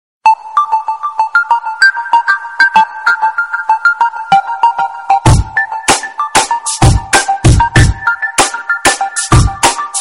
Android, Arabisk Musik, Musik